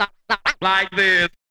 Vocal.wav